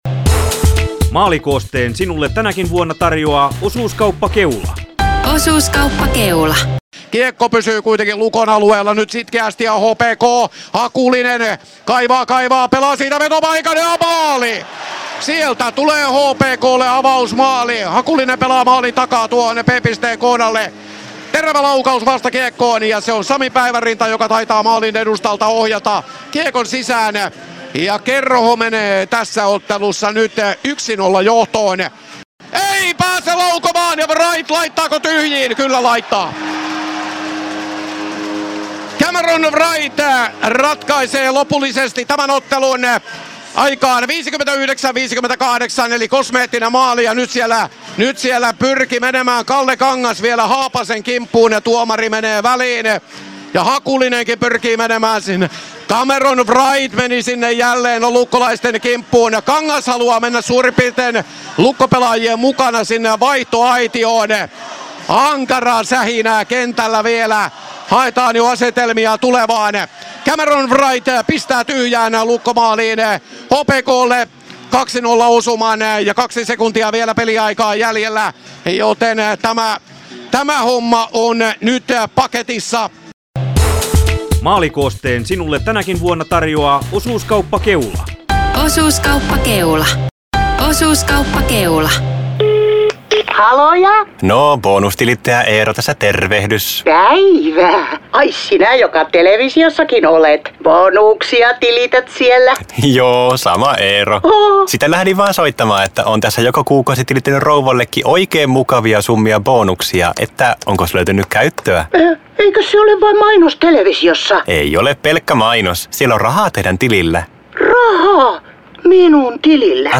Maalikooste HPK-Lukko 21.3.2026